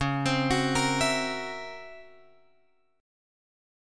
C# Minor Major 13th